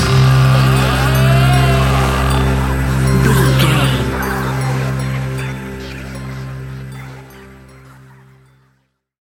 Aeolian/Minor
ominous
eerie
strings
synthesiser
percussion
electric guitar
electric organ
harp
tense
horror music